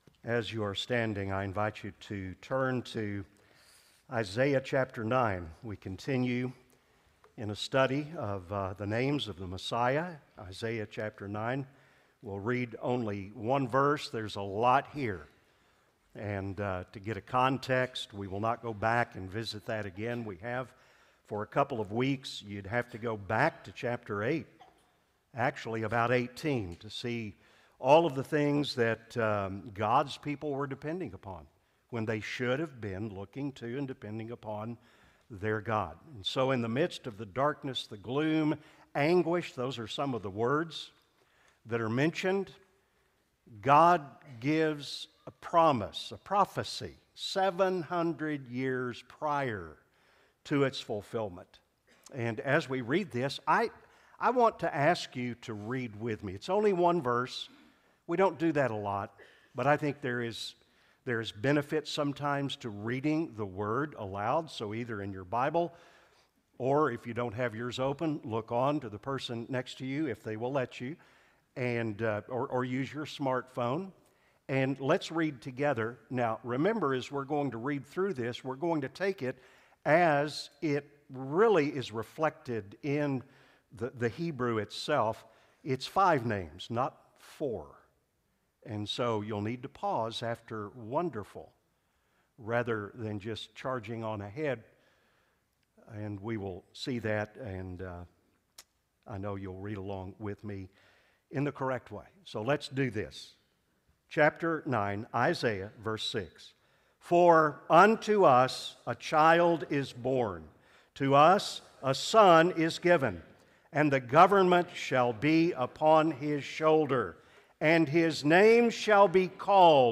Sermons – HERITAGE Baptist Church